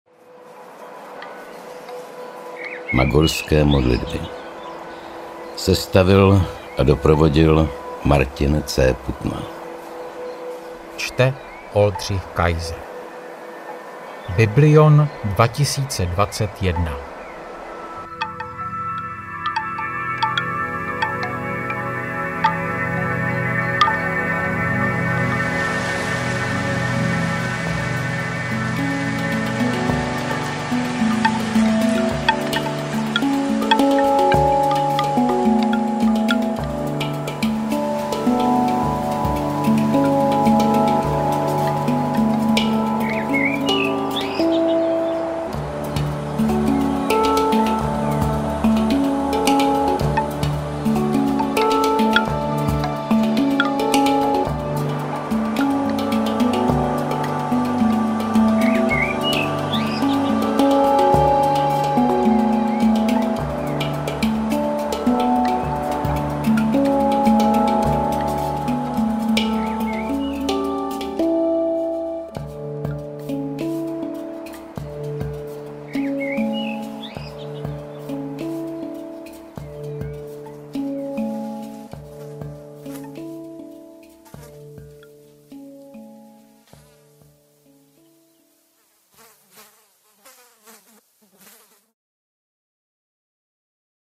Magorské modlitby Audiokniha
Číta: Martin C. Putna